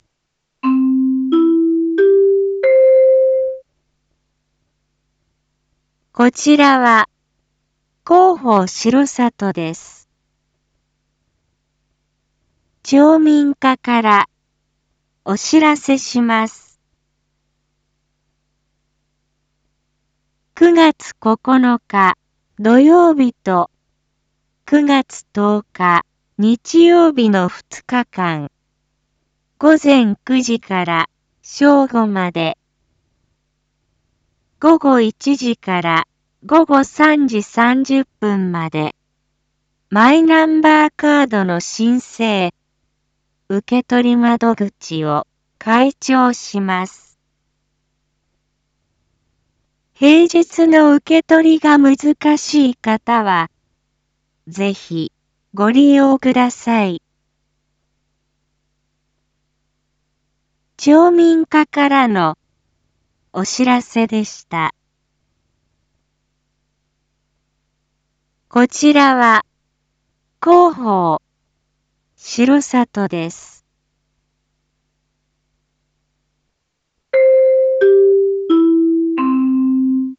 一般放送情報
Back Home 一般放送情報 音声放送 再生 一般放送情報 登録日時：2023-09-08 19:01:21 タイトル：マイナンバーカード インフォメーション：こちらは、広報しろさとです。